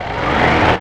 FeelFalconEngineStartSound.wav